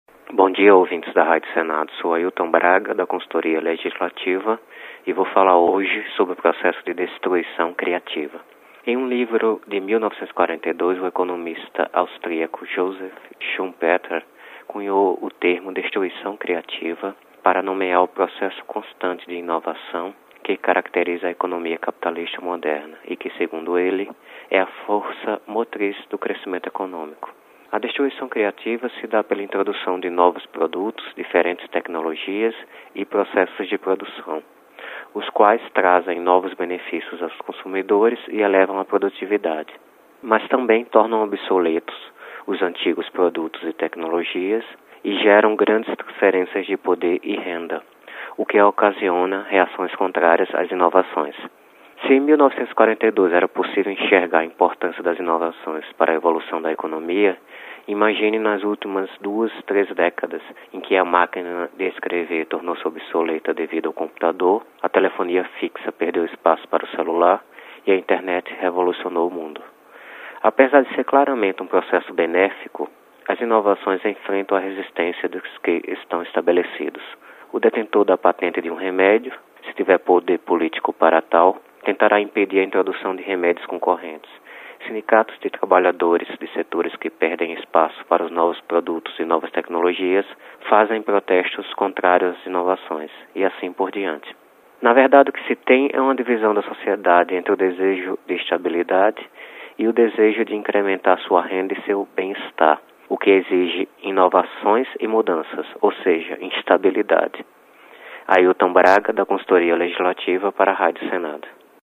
Comentário Econômico: Processo de destruição criativa